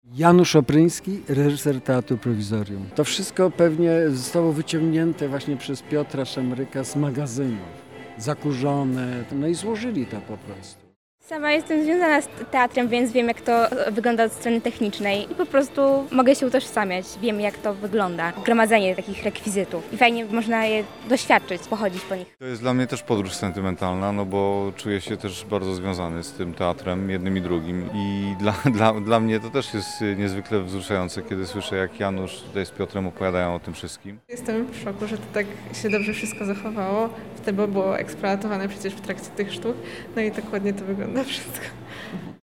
Nasz reporter był na miejscu i rozmawiał ze zwiedzającymi i autorami.